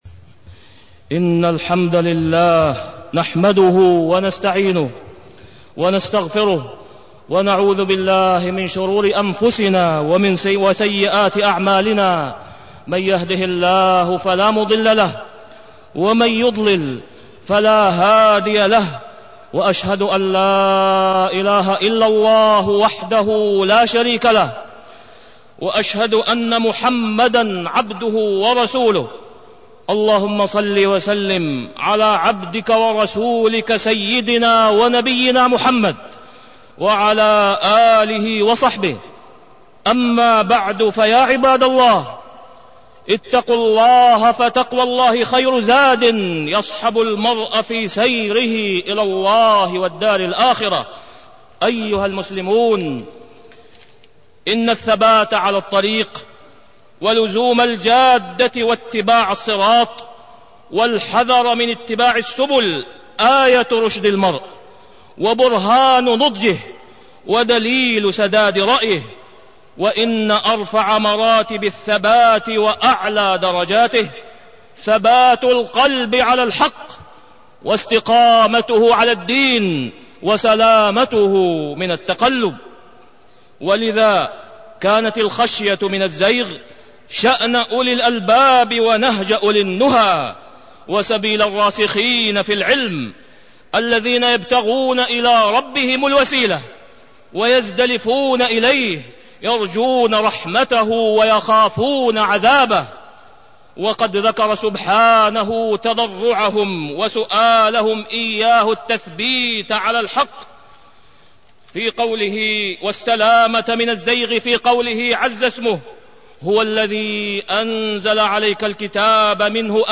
تاريخ النشر ٣٠ جمادى الأولى ١٤٢٣ هـ المكان: المسجد الحرام الشيخ: فضيلة الشيخ د. أسامة بن عبدالله خياط فضيلة الشيخ د. أسامة بن عبدالله خياط السلامة من الزيغ